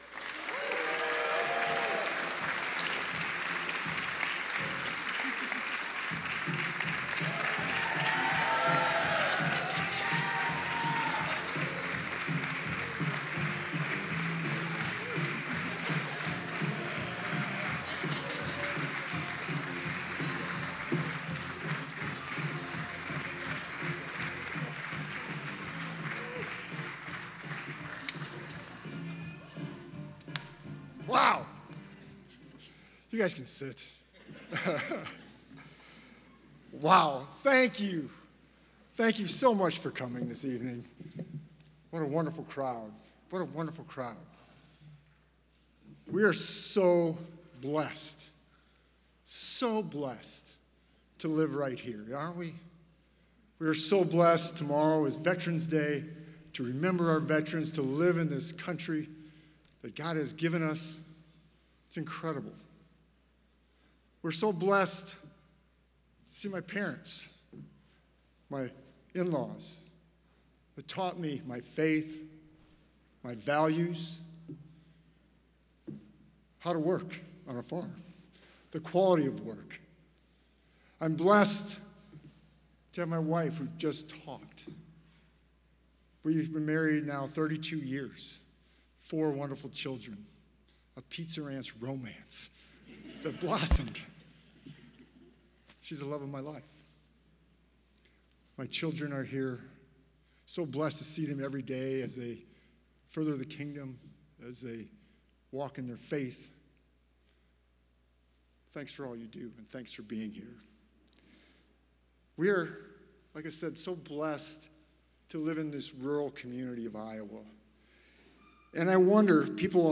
Feenstra spoke to a crowd tonight on the Dordt University campus.